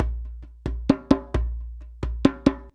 089DJEMB01.wav